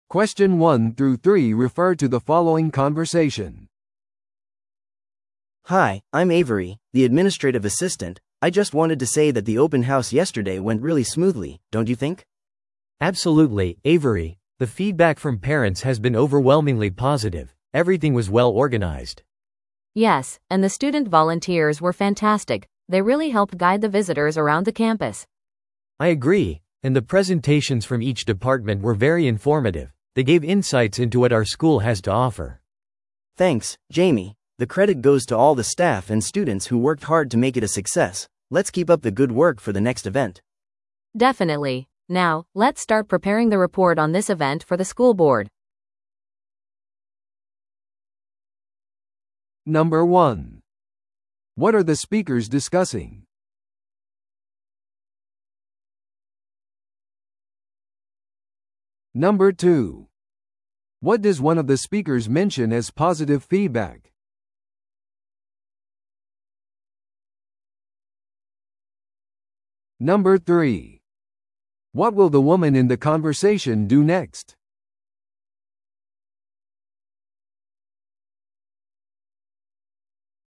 No.3. What will the woman in the conversation do next?